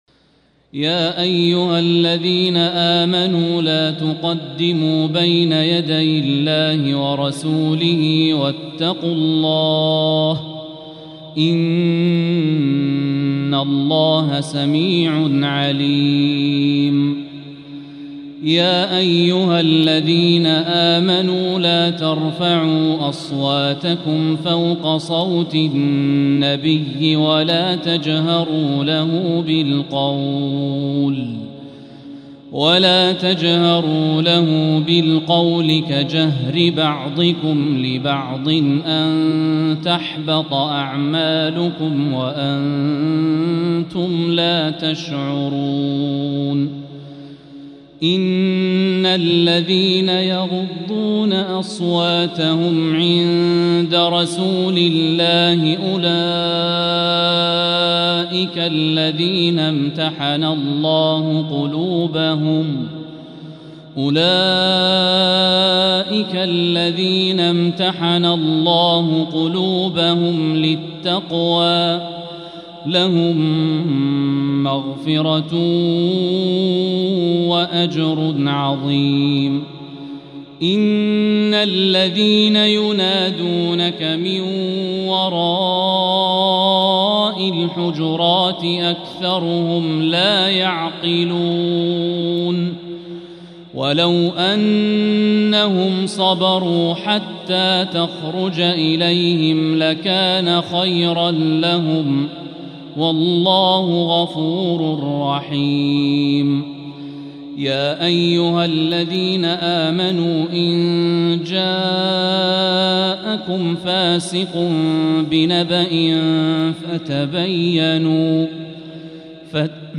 سورة الحجرات | تهجد الحرم المكي عام 1445هـ